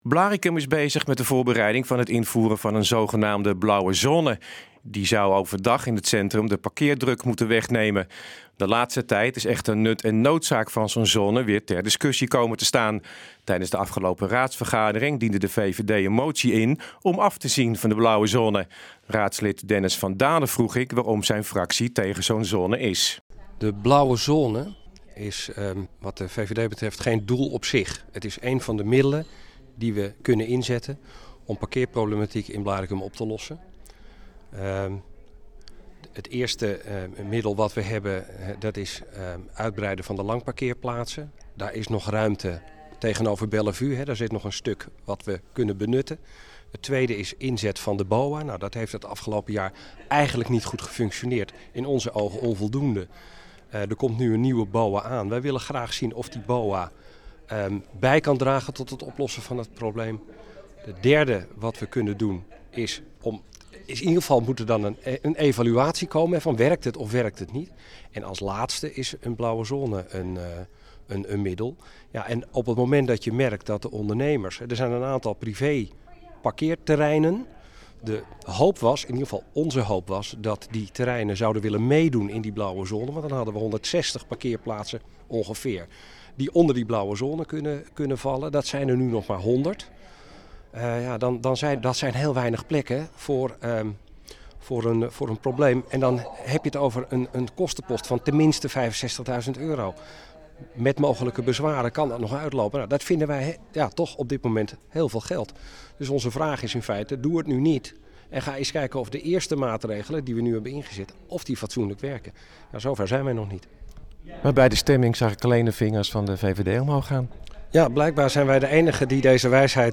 De VVD in Blaricum heeft afgelopen raadsvergadering een motie ingediend om af te zien van het invoeren van een blauwe zone. Raadslid Dennis van Dalen legt uit waarom.